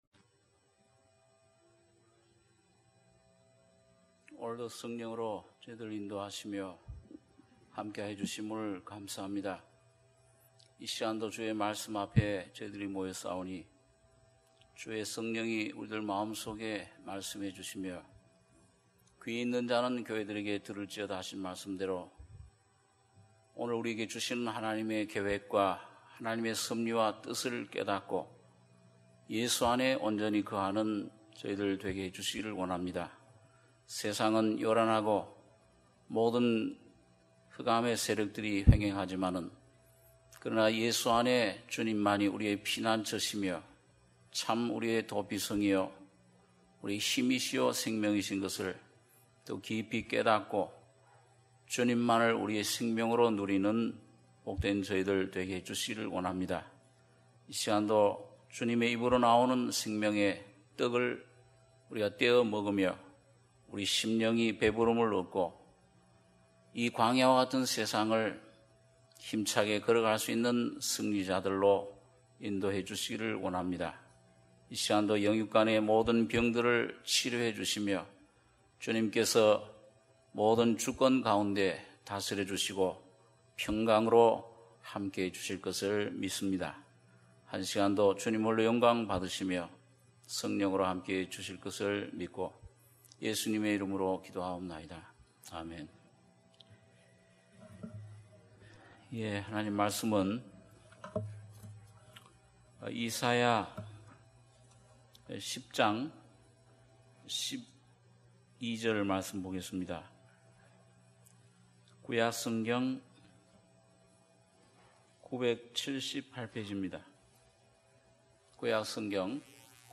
수요예배 - 이사야 10장 12절~21절